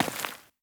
added stepping sounds
Glass_Grit_Mono_01.wav